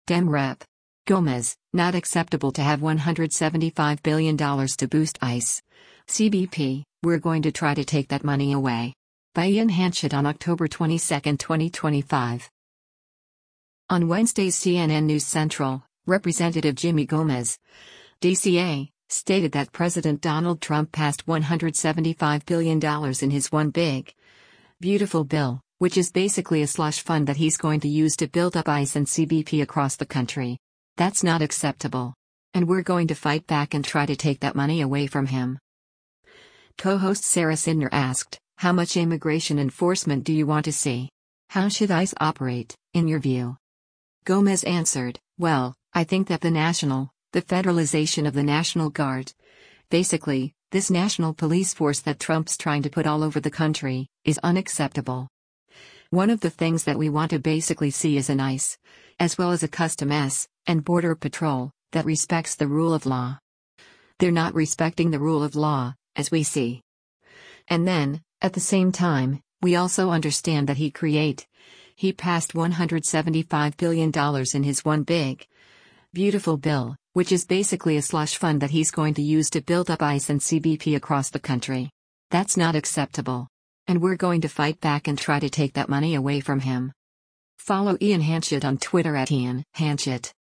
Co-host Sara Sidner asked, “How much immigration enforcement do you want to see? How should ICE operate, in your view?”